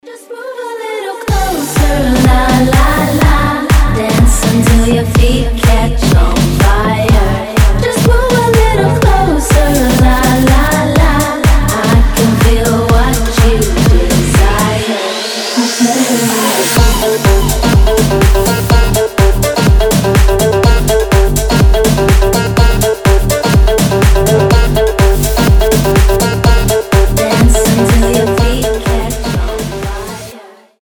• Качество: 320, Stereo
громкие
заводные
женский голос
EDM
Club House
восточные
Vocal House
ремиксы